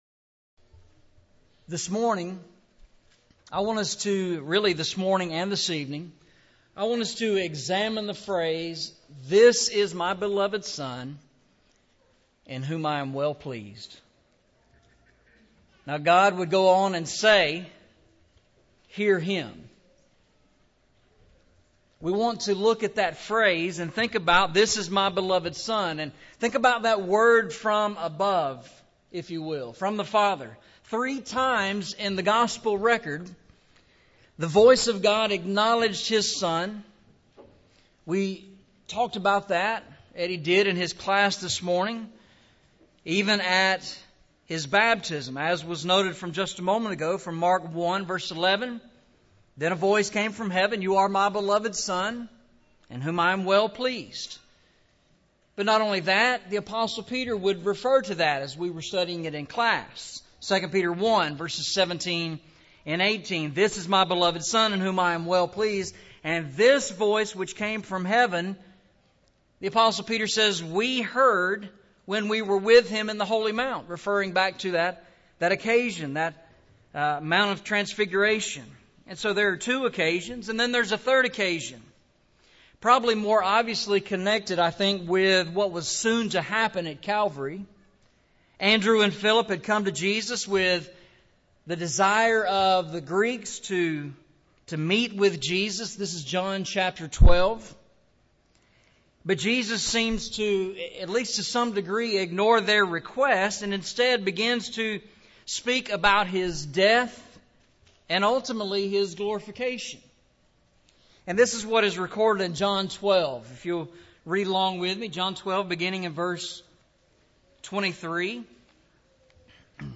Eastside Sermons Service Type: Sunday Morning « A Beautiful Future